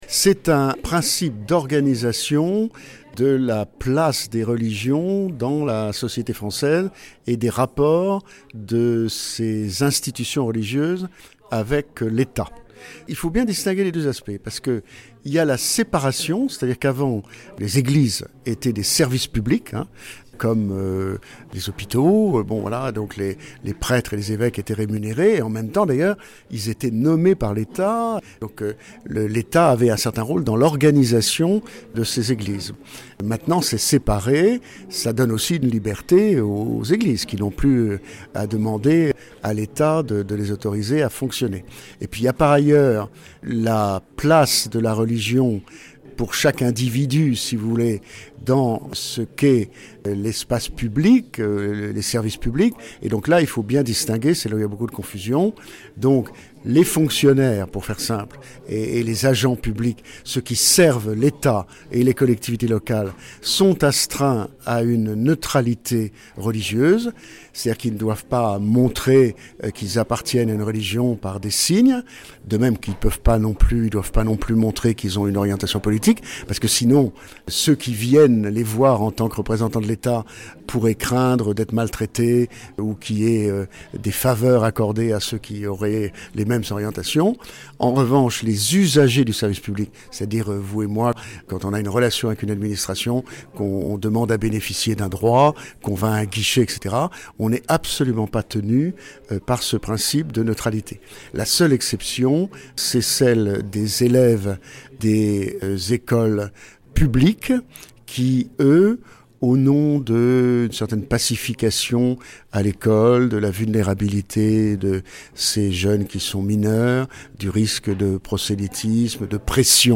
Marseille: riche débat à l’Institut Catholique de la Méditerranée sur ce que devient la laïcité